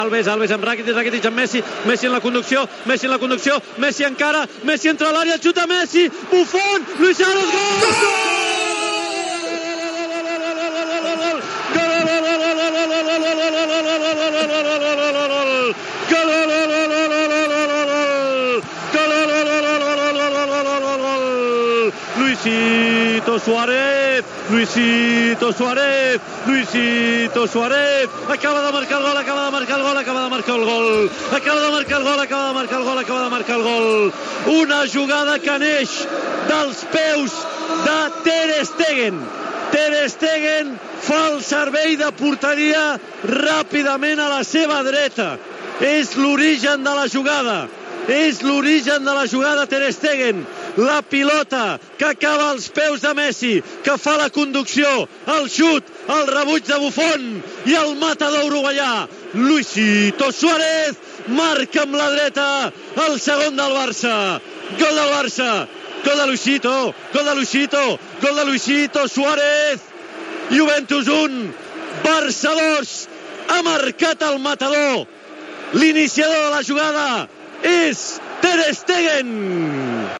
Transmissió de la final de la Copa d'Europa de futbol masculí, des de l'Olympiastadion de Berlín, entre el Futbol Club Barcelona i la Juventus de Milàs.
Narració del gol de Luis Suárez i reconstrucció de la jugada, (2-1)
Esportiu